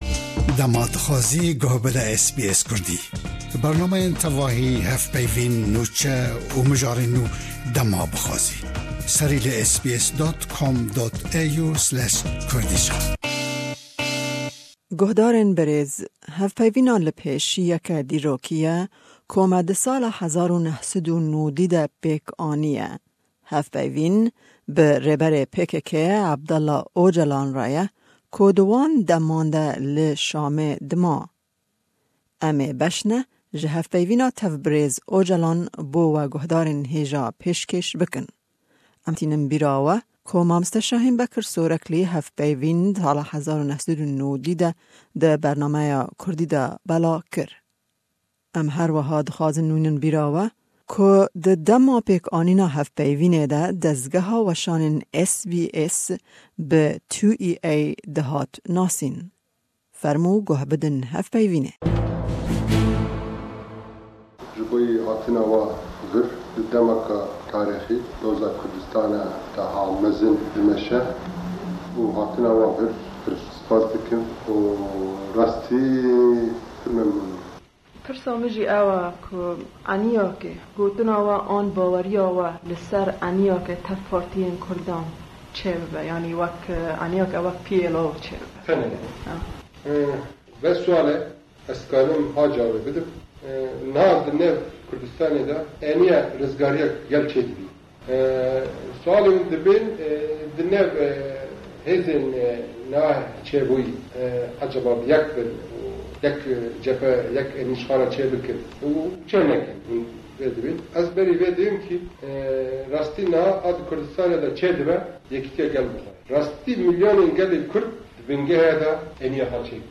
Interview with Abdullah Öcalan